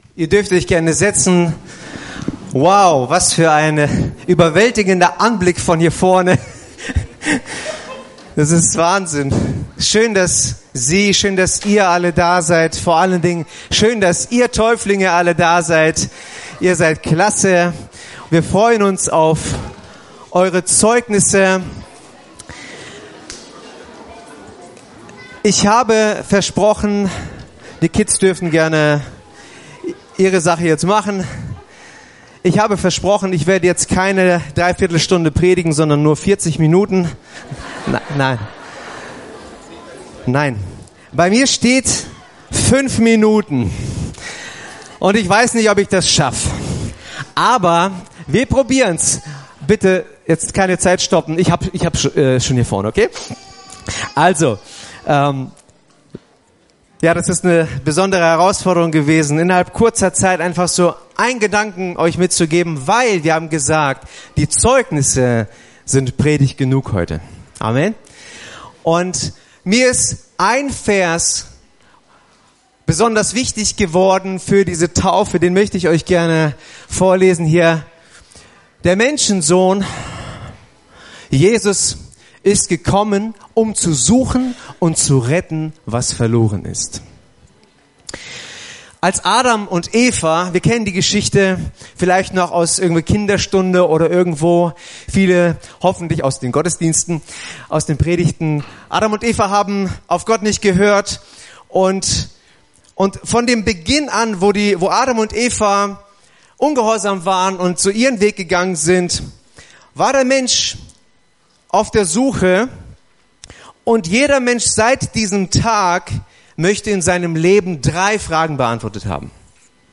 Predigten
Taufgottesdienst am 20.10.2019